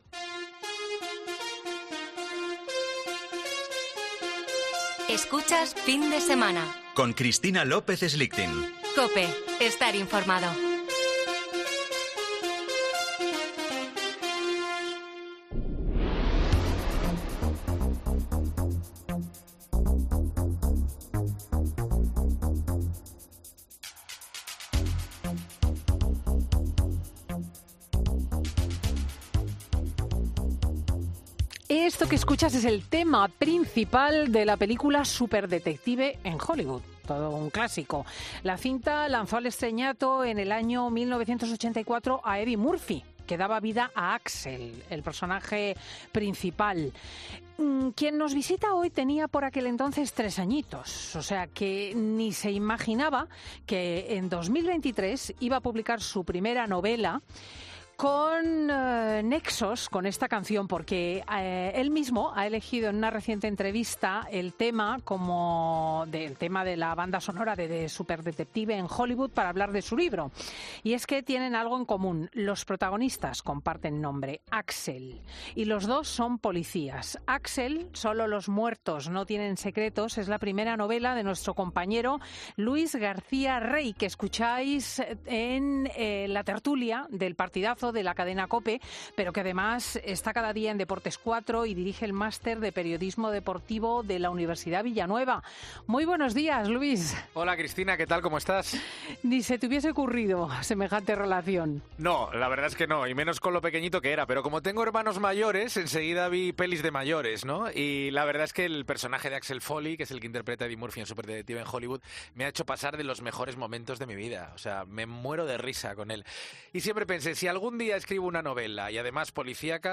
Un thriller policíaco que ha presentado en los micrófonos de Fin de Semana